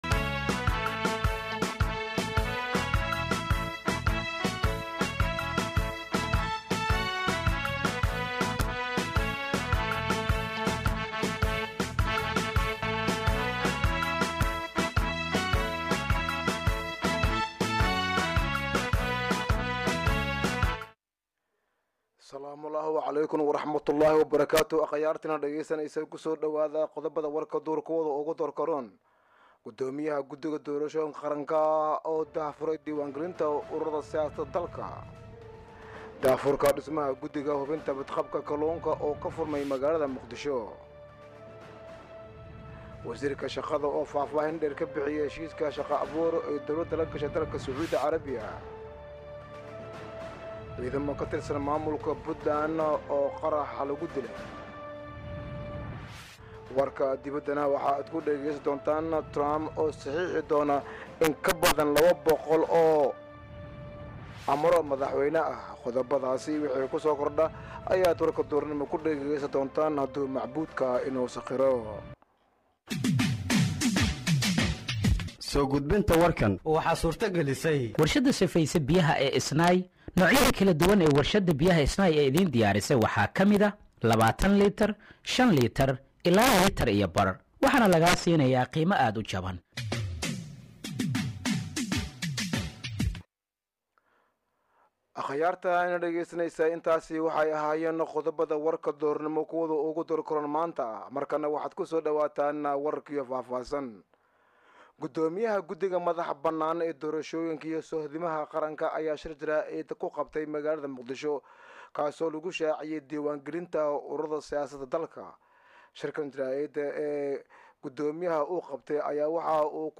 Dhageeyso Warka Duhurnimo ee Radiojowhar 20/01/2025